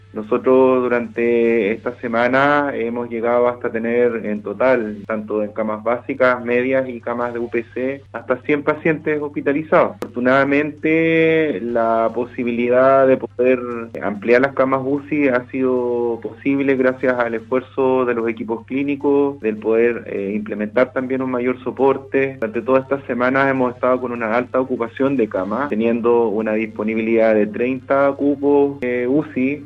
En conversación con Radio Sago